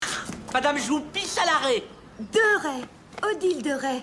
Un clip de audio francés con lenguaje coloquial, probablemente de un sketch de comedia o película.
Etiquetas: french, comedy, voice